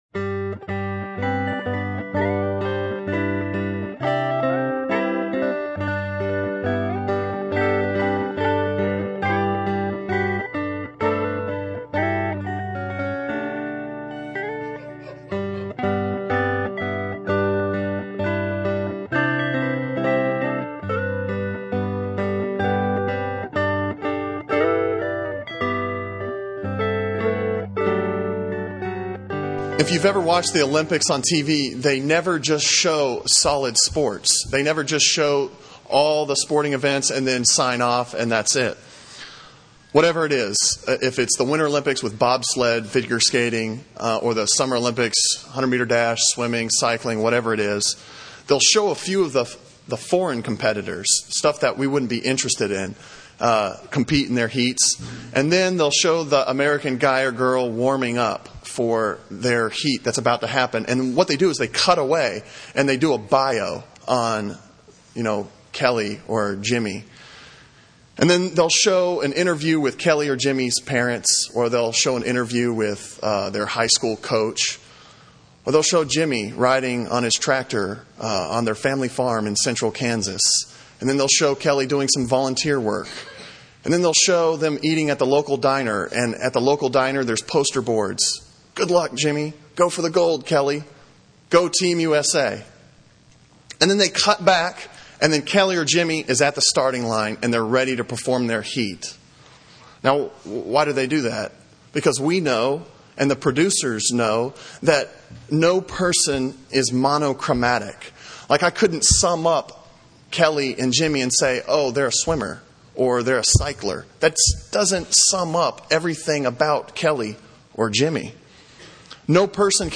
Sermon on Isaiah 43:14-28 from March 18